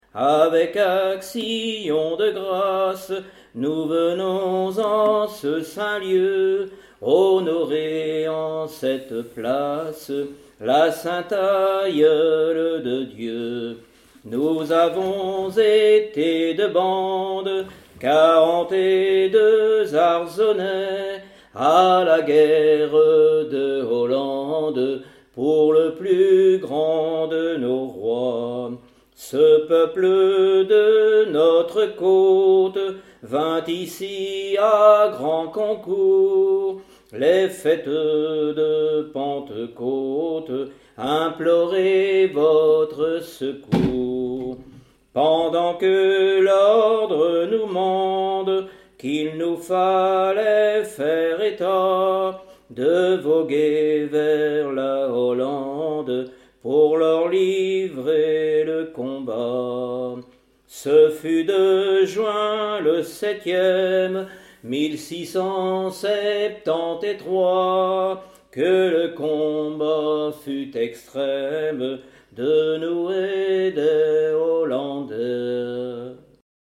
prière, cantique
Genre strophique
Pièce musicale inédite